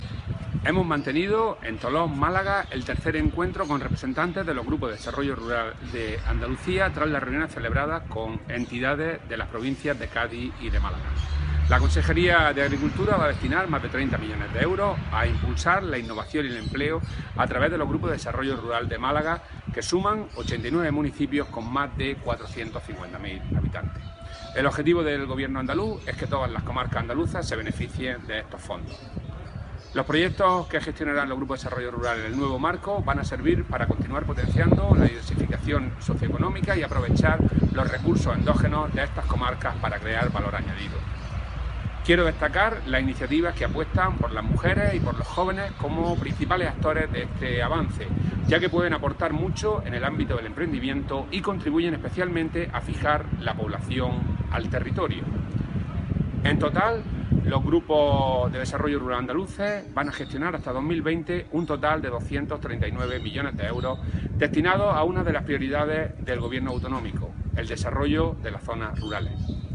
Declaraciones de Rodrigo Sánchez sobre su encuentro con Grupos de Desarrollo Rural de Málaga